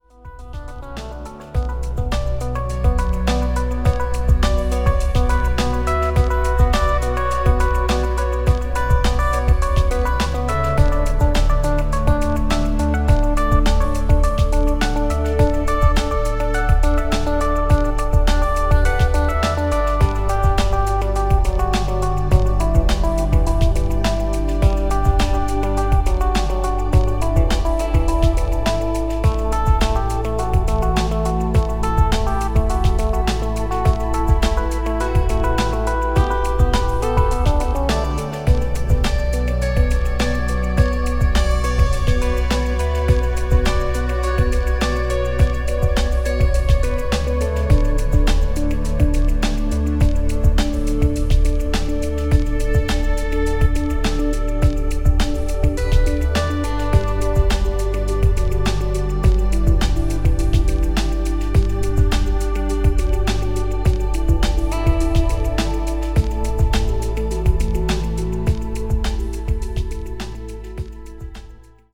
The untreated recording.